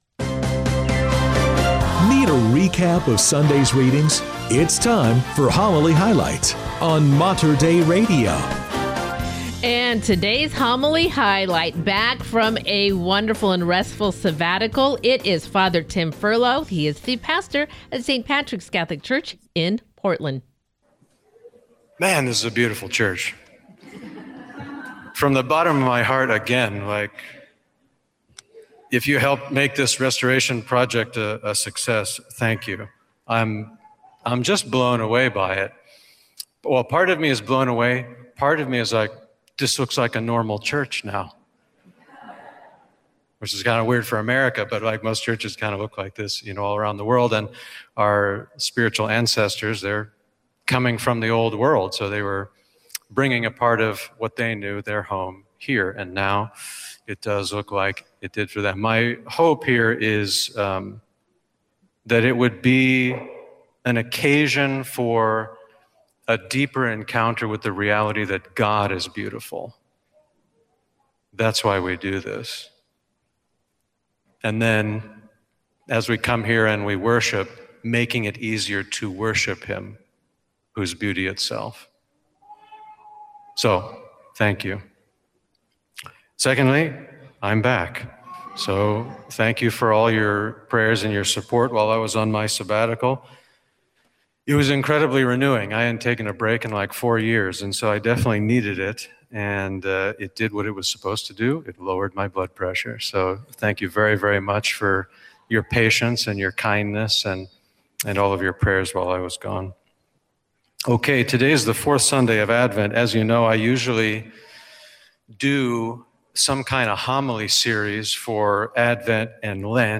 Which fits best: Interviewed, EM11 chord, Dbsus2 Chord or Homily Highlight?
Homily Highlight